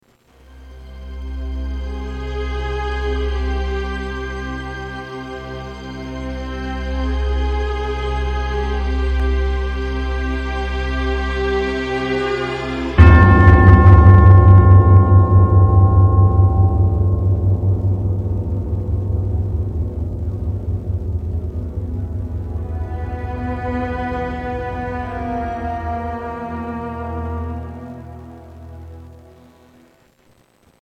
Television Music